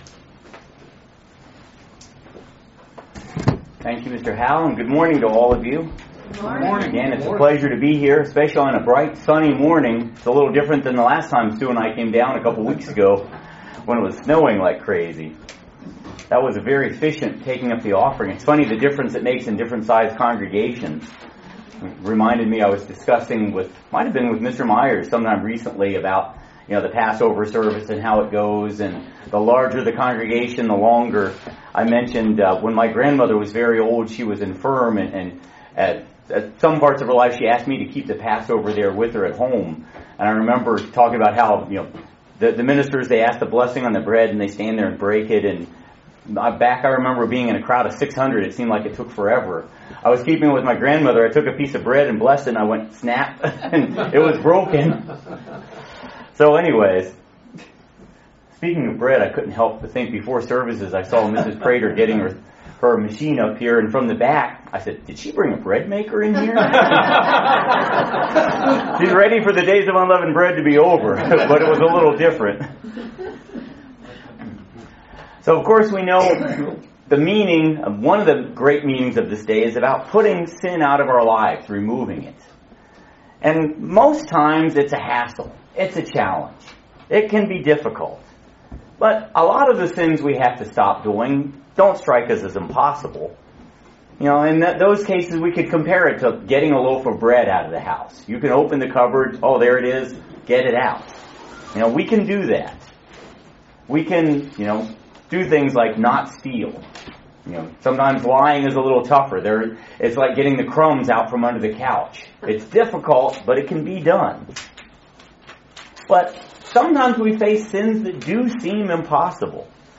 Excellent Sermon on the lessons we can learn from the Israelite's and there taking of Jerricho.